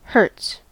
Uttal
Synonymer cycle per second Uttal US UK: IPA : /hɜː(ɹ)ts/ Ordet hittades på dessa språk: engelska Ingen översättning hittades i den valda målspråket.